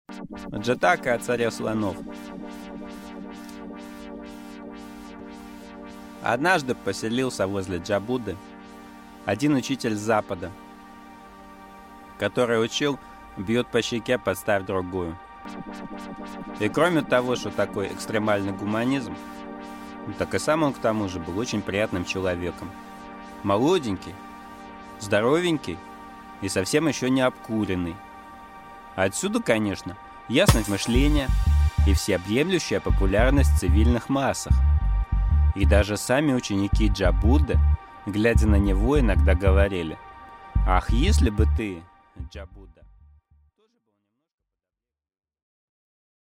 Аудиокнига Джатака о царе слонов | Библиотека аудиокниг